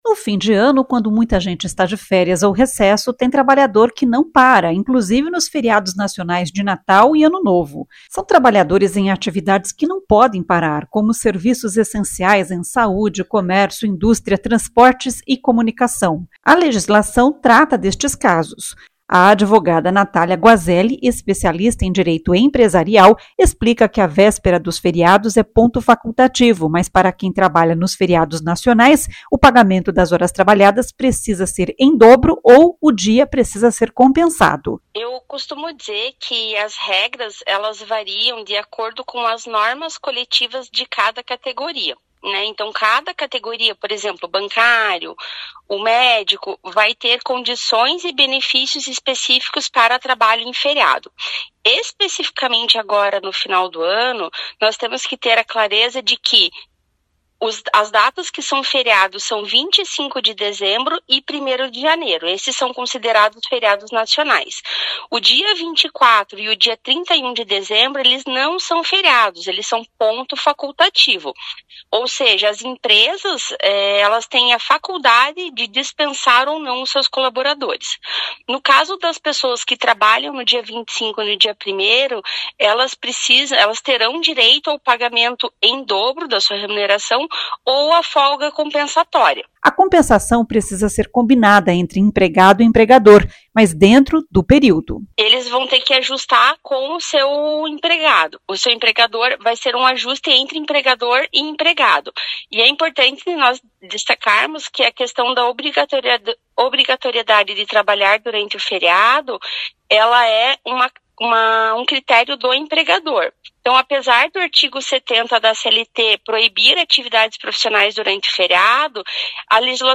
A advogada também explica como funcionam as férias coletivas.